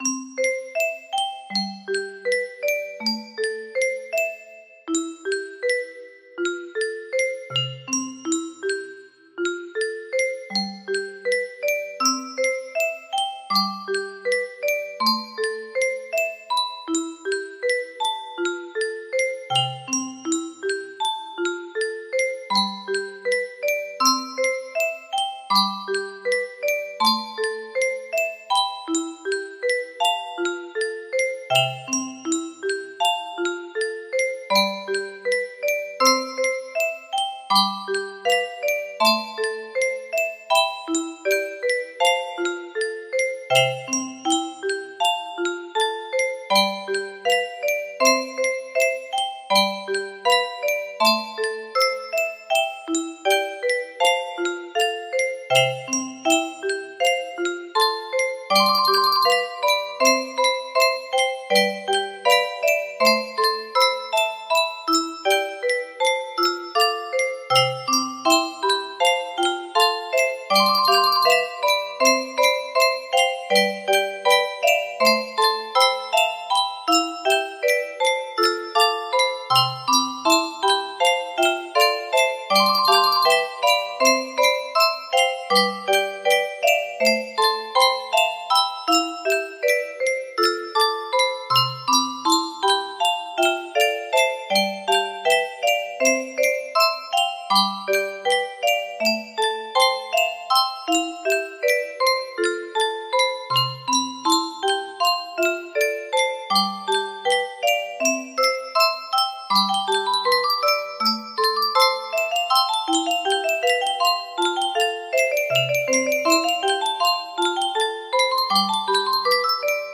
canon- canon music box melody